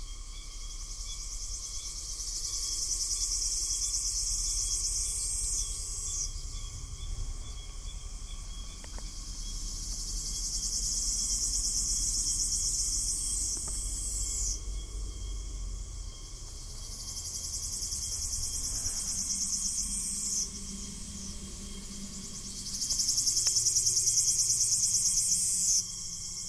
リュウキュウアブラゼミの鳴き声
鳴き声は鍋に付いたススを落とす時の音に似ていると言う事から「ナービカチカチ」の方言名がある。
＊ 沖縄の動物・植物達のコーナー 録音：SonyリニアPCMレコーダーPCM-M10 国頭村にて録音
ryukyuaburazmemi-call.mp3